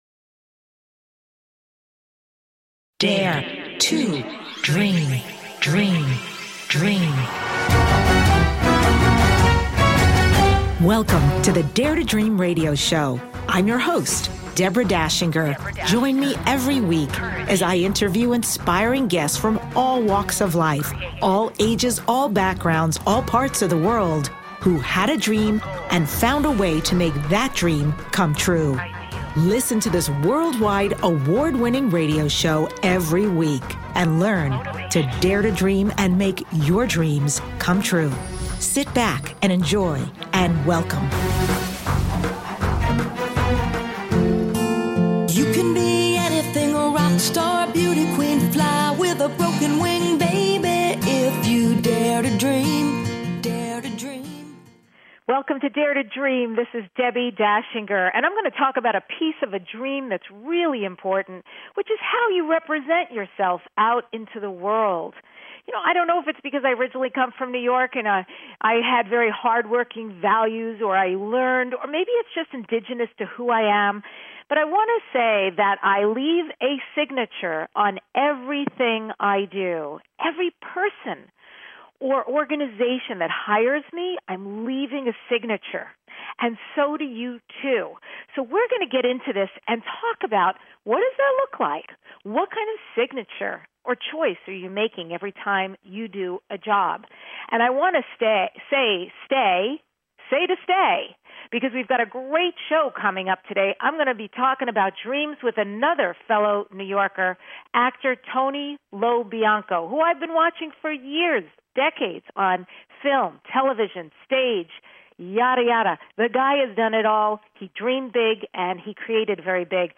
Talk Show Episode
Guest, Tony Lo Bianco